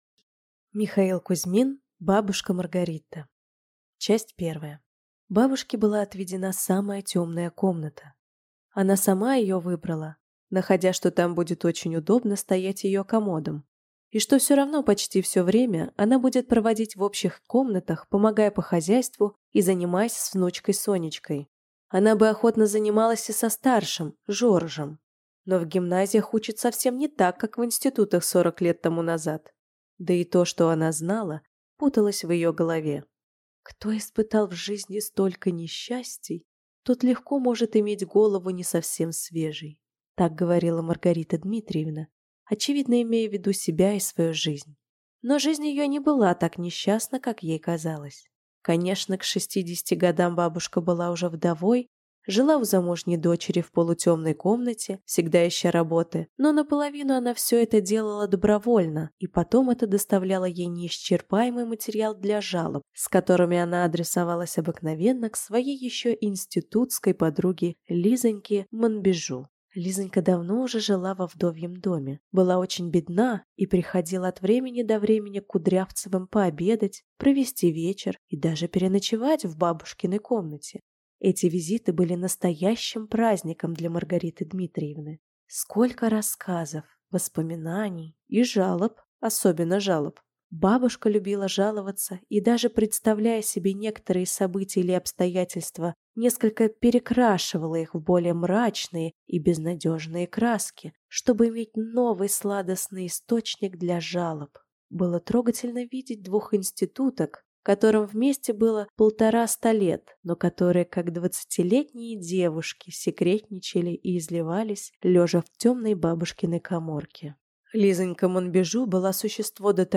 Aудиокнига Бабушка Маргарита